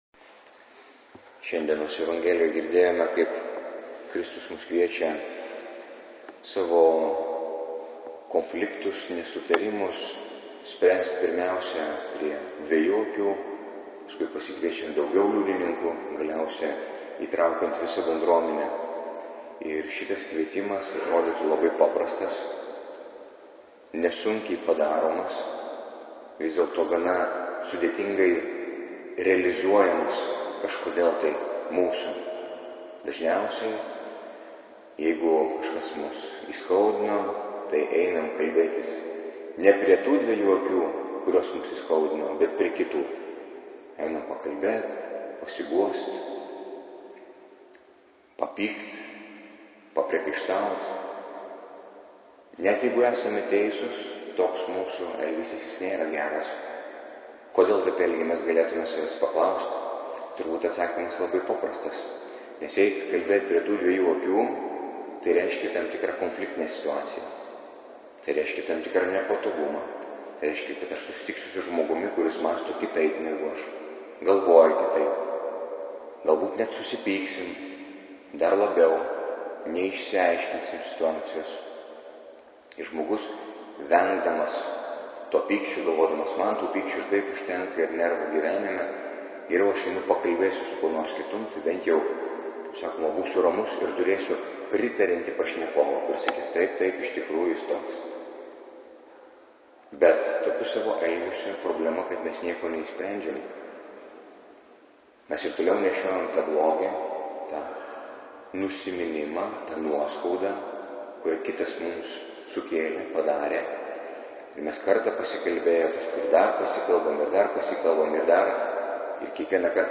Audio pamokslas Nr1: